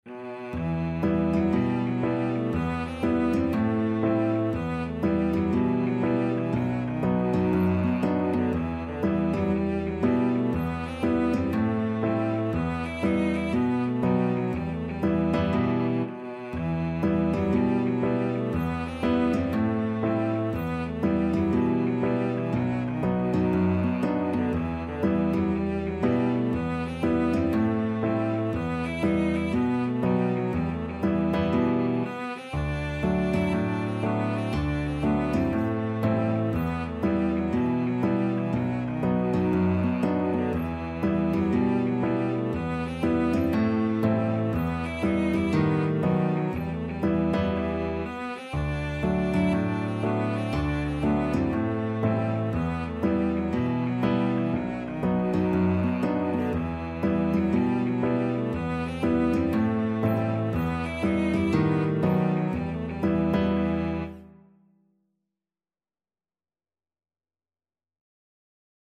Free Sheet music for Flexible Ensemble and Piano - 4 Players and Piano
Cello
Guitar
Piano
E minor (Sounding Pitch) (View more E minor Music for Flexible Ensemble and Piano - 4 Players and Piano )
2/2 (View more 2/2 Music)
With a swing = c.60
Traditional (View more Traditional Flexible Ensemble and Piano - 4 Players and Piano Music)